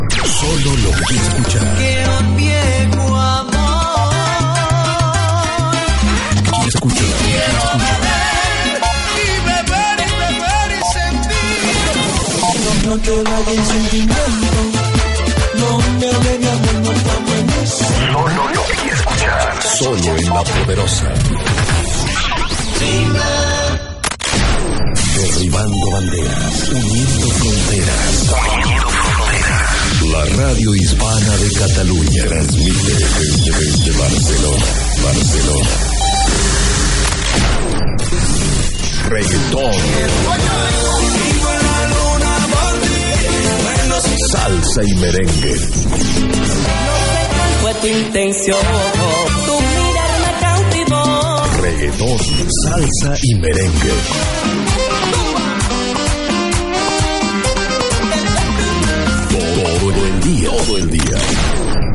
Indicatiu de l' emissora